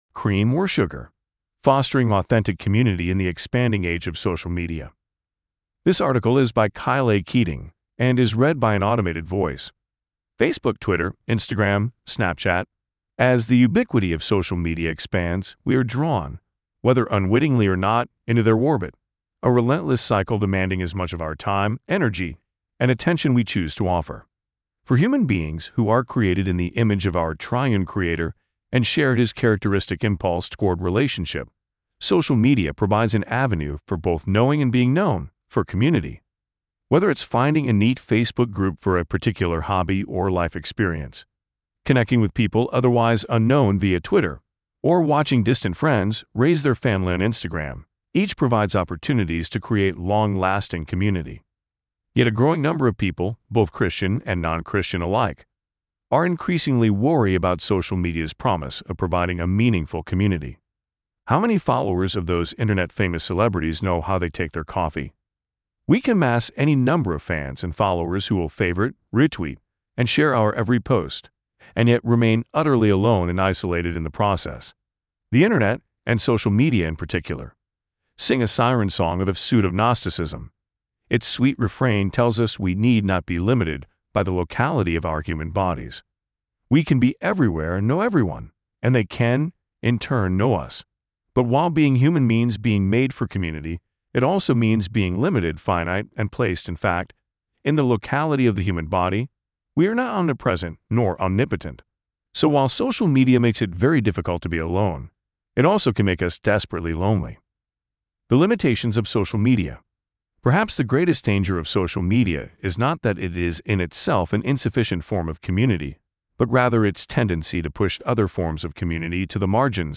AI-Cream-AI-Fostering-Authentic-Community-in-the-Expanding-Age-of-Social-Media.mp3